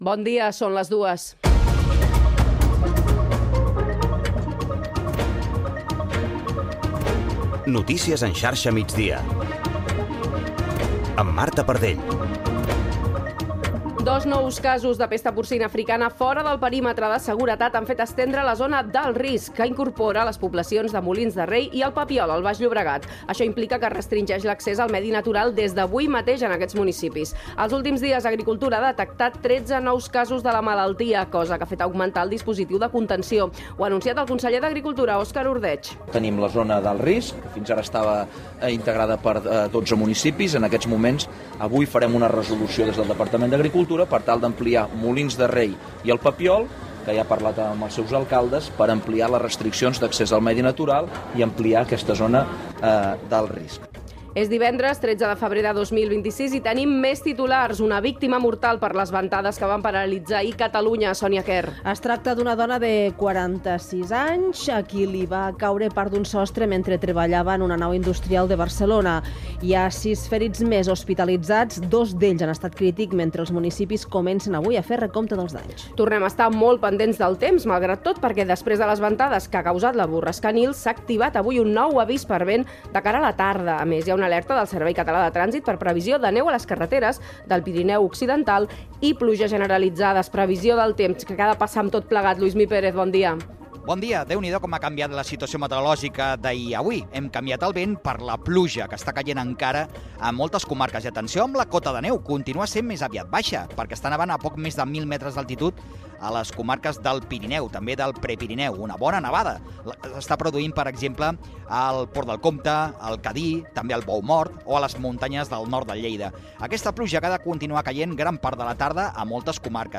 Informatiu
FM